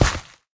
gravel3.ogg